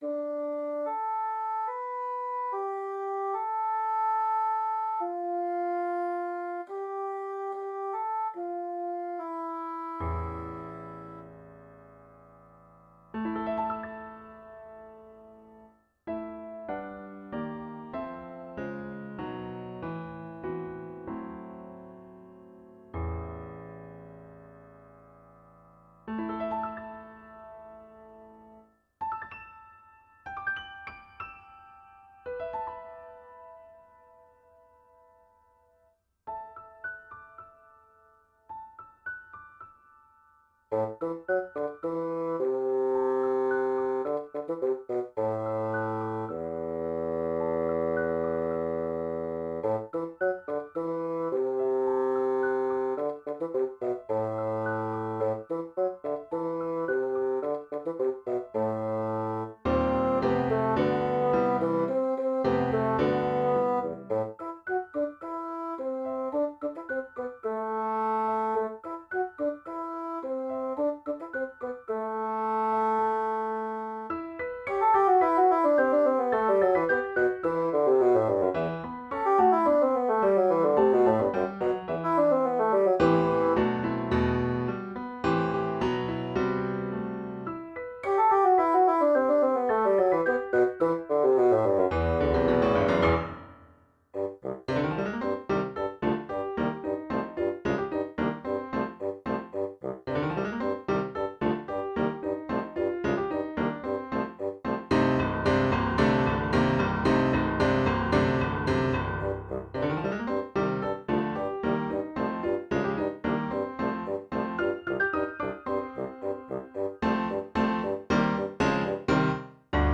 Bassoon